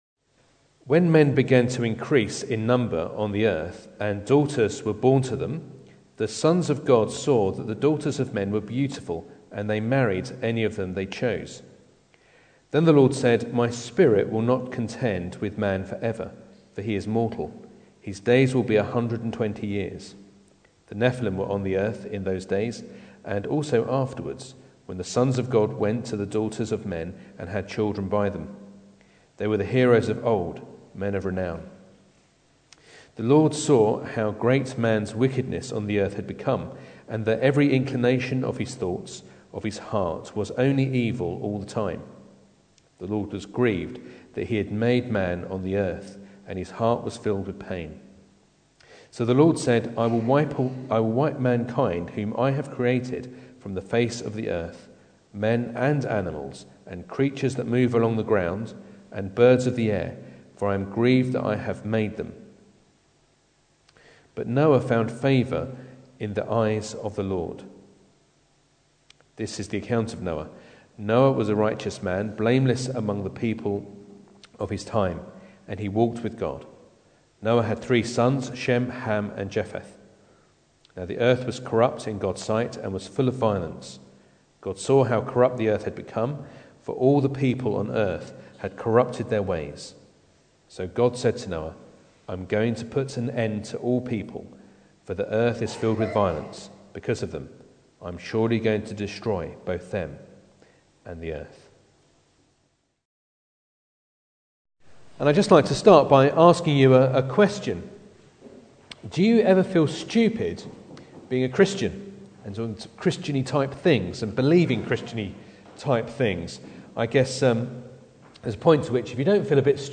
Genesis 6:1-13 Service Type: Sunday Morning Bible Text